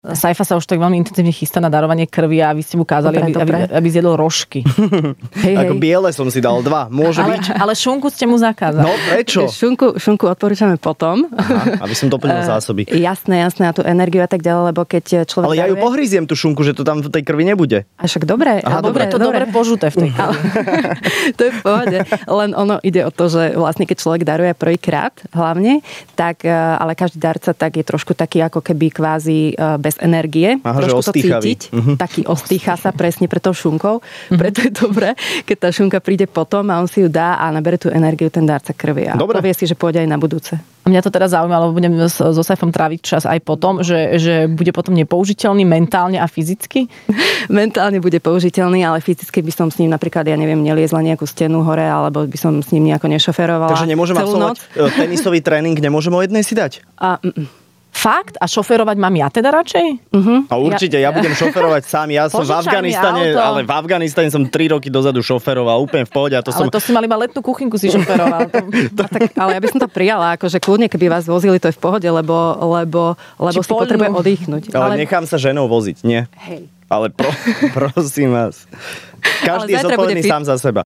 Vypočuj si, ako sa na to v Rannej šou chystal!